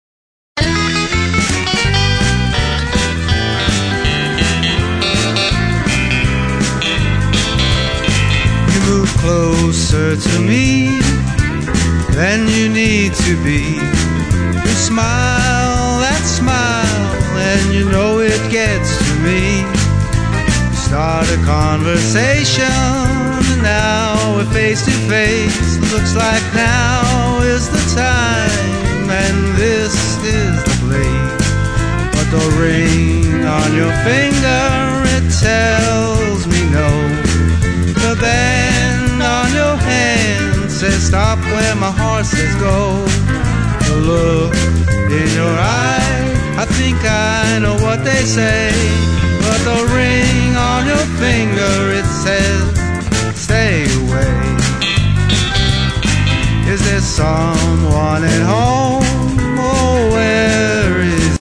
mp3 / S / Alt Country